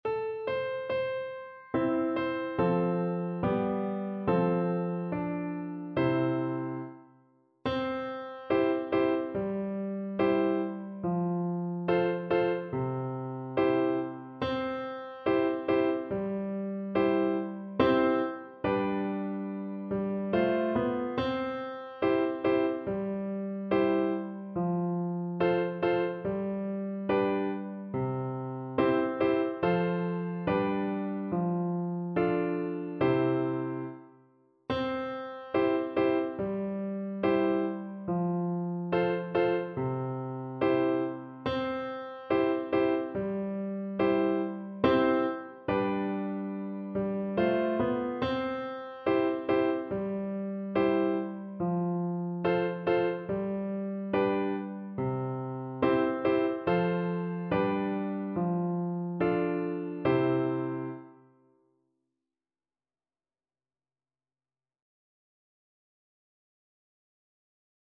Flute
C major (Sounding Pitch) (View more C major Music for Flute )
Moderato
4/4 (View more 4/4 Music)
Traditional (View more Traditional Flute Music)
devils_nine_questions_FL_kar3.mp3